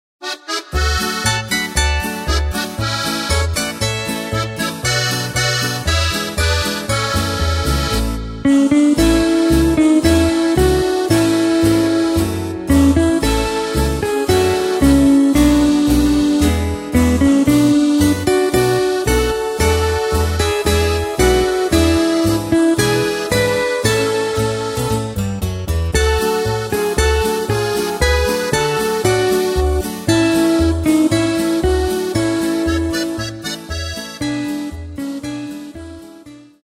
Takt:          2/4
Tempo:         117.00
Tonart:            A
Polka-Schlager aus dem Jahr 1979!